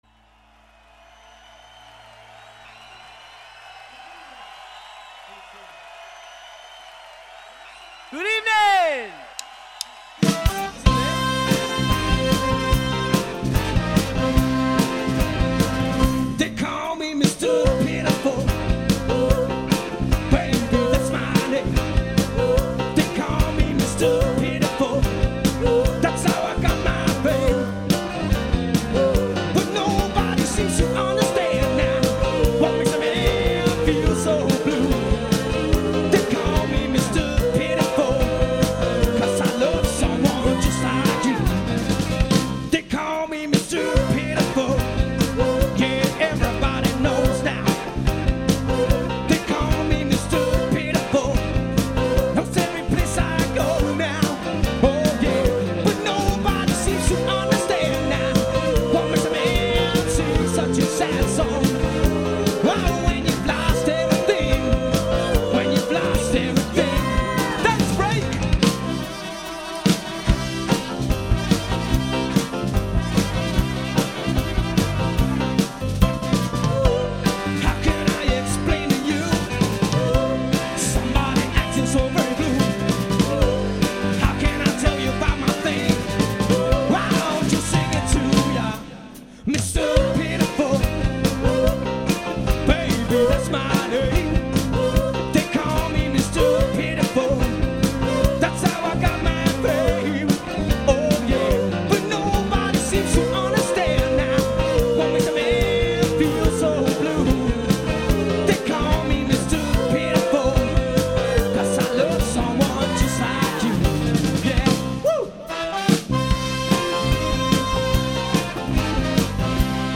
classic soul tracks